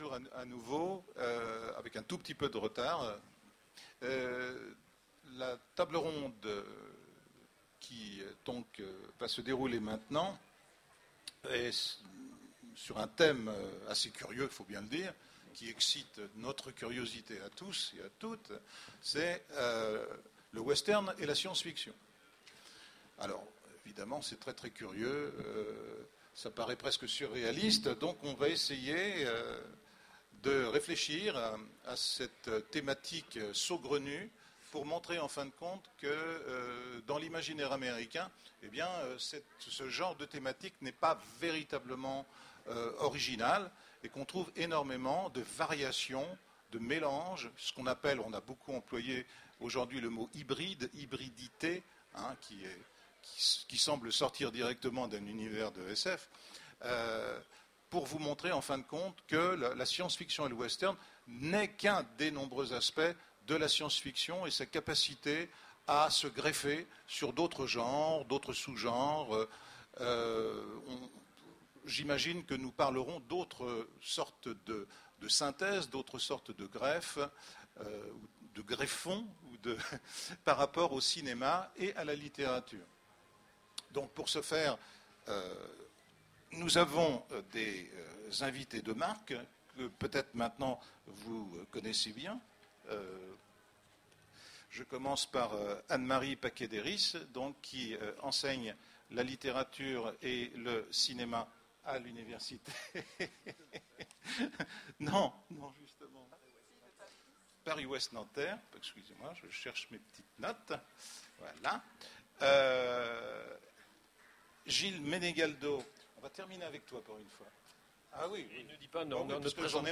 Voici l'enregistrement de la conférence " Western et SF " aux Utopiales 2010. Le western repose essentiellement sur le concept américain de frontière qui s’impose en forgeant une véritable mythologie populaire.